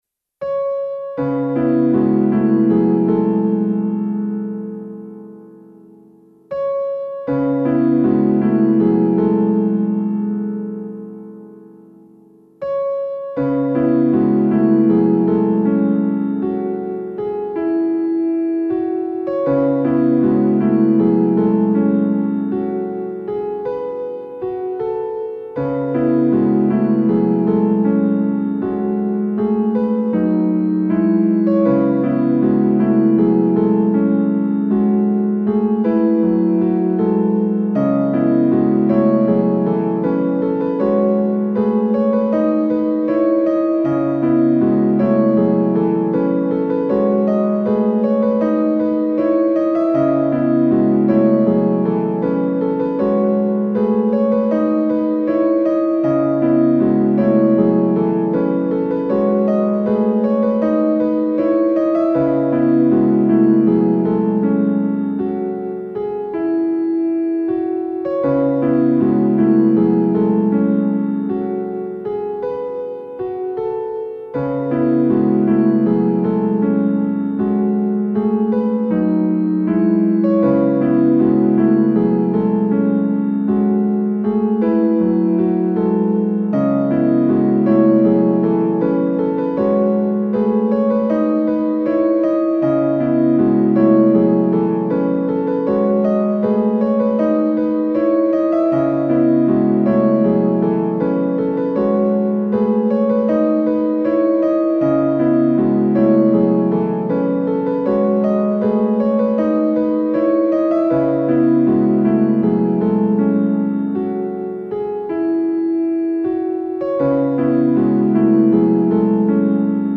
Solo Piano in C#minor